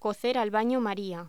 Locución: Cocer al baño maría
voz